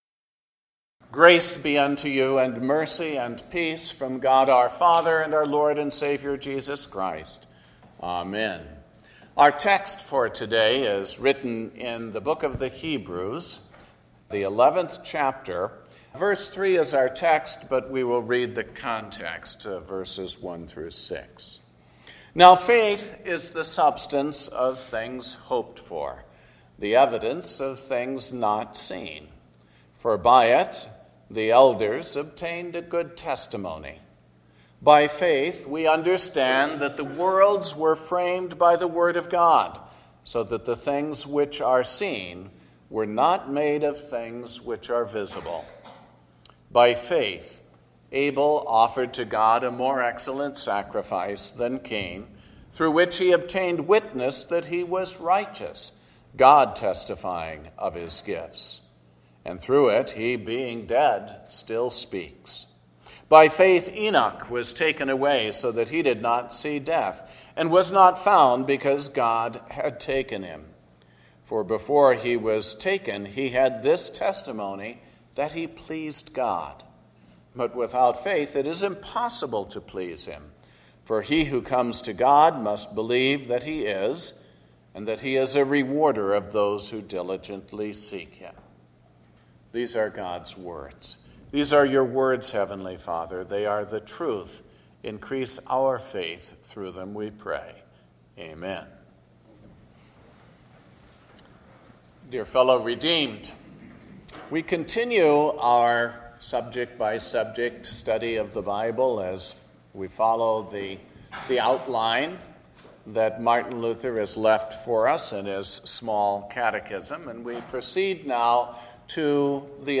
The Third Sunday After Epiphany – Sermon based on Hebrews 11:3